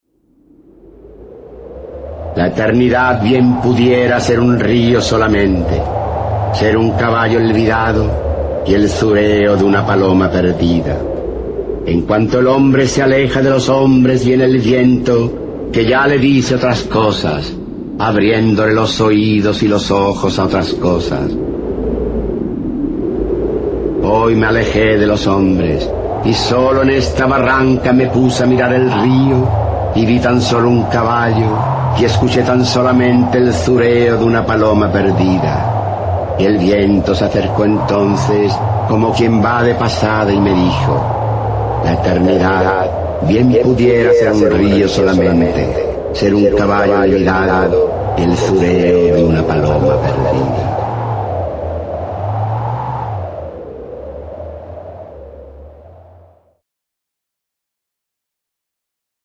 Archivo de sonido con la voz del escritor español Rafael Alberti, quien recita su poema “Balada de lo que el viento dijo" (Baladas y canciones del Paraná, 1954).
Se recomienda este recurso para promover un encuentro placentero de los estudiantes con el texto literario, recitado en la voz de su autor.